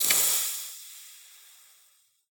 Ilmarinen,blacksmith,forge,hotiron,water,squelch,sizzle,rough,popping.M.ogg